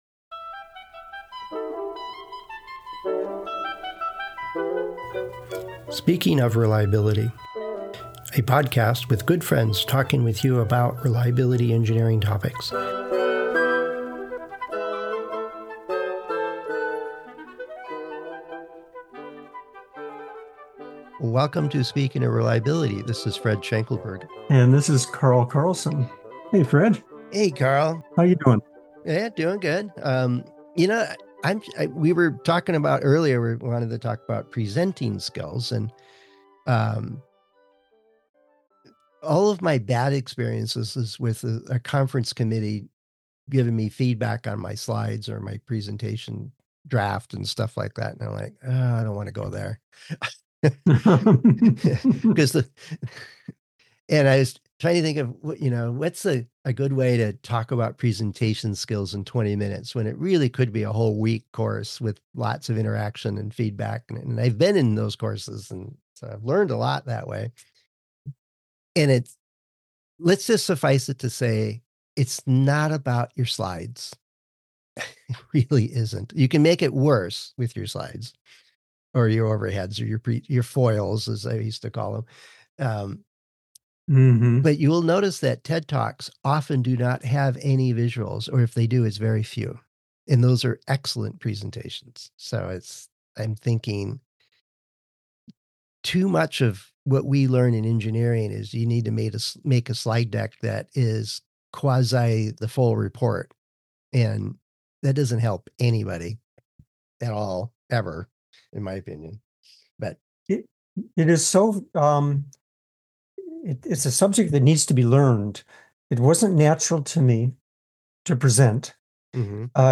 Friends Discussing Reliability Engineering Topics